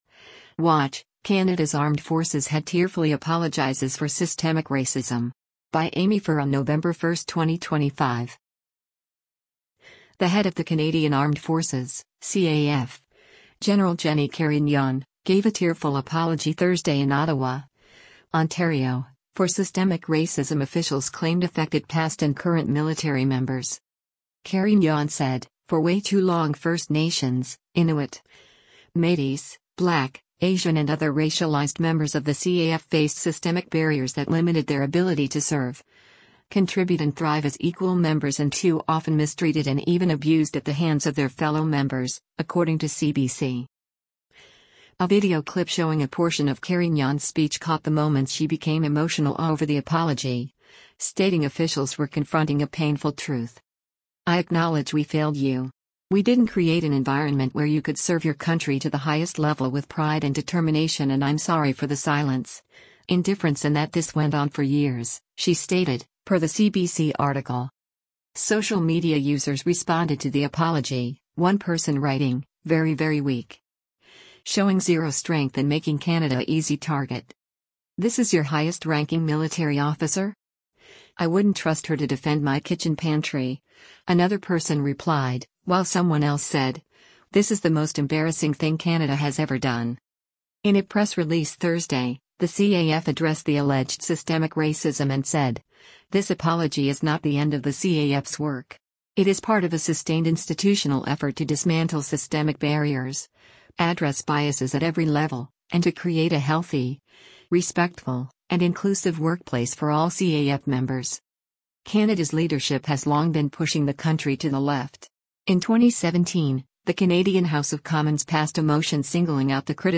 WATCH — Canada’s Armed Forces Head Tearfully Apologizes for ‘Systemic Racism’
The head of the Canadian Armed Forces (CAF), General Jennie Carignan, gave a tearful apology Thursday in Ottawa, Ontario, for “systemic racism” officials claimed affected past and current military members.
A video clip showing a portion of Carignan’s speech caught the moments she became emotional over the apology, stating officials were “confronting a painful truth”: